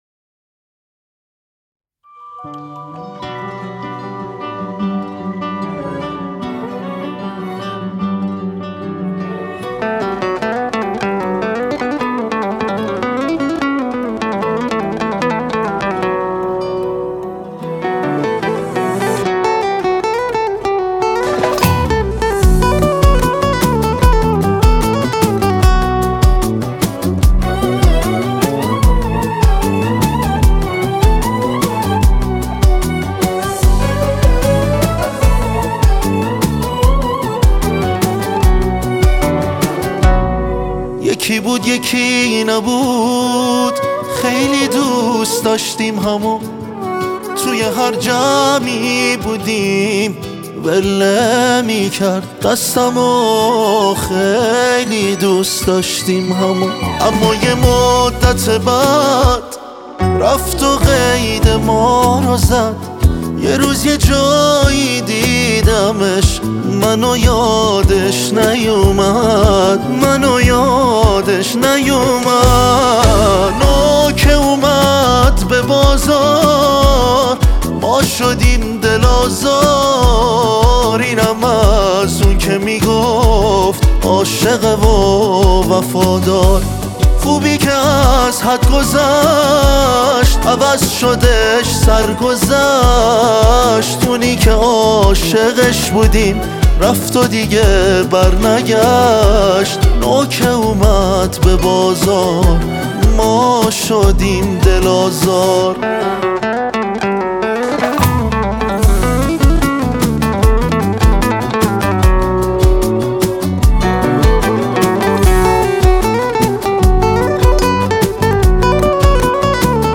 یه موزیک بغضی و چس ناله ای